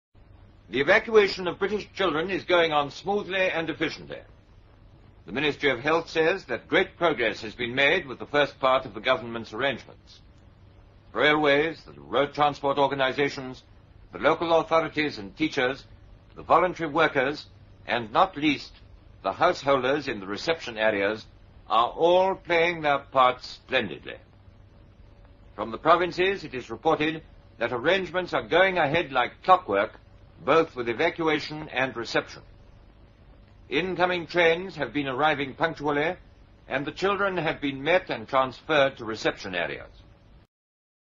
Much of this type of information was brought to the British people in a calm and clear manner by Alvar Lidell.
Alvar Lidell reports on the Evacuation of Children (September 1939)
BBC-News-Alvar-Liddell-Reports-On-Evacuation-Of-Children-September-1-1939.mp3